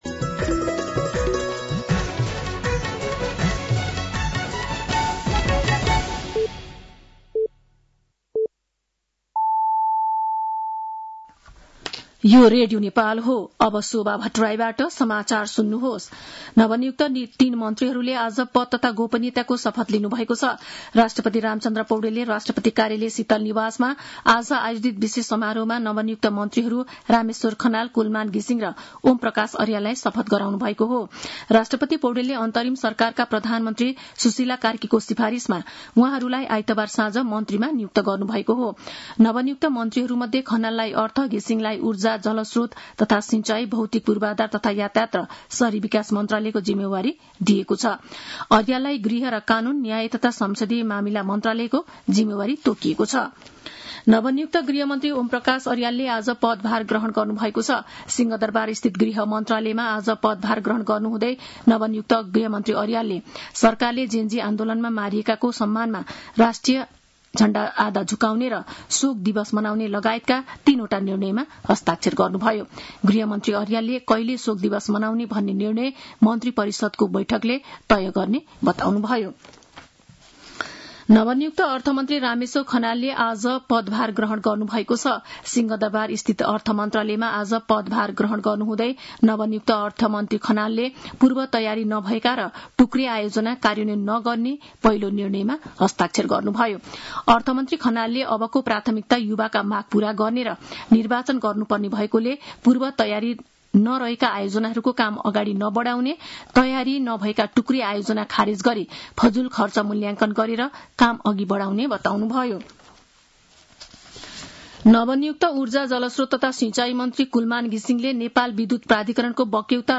साँझ ५ बजेको नेपाली समाचार : ३० भदौ , २०८२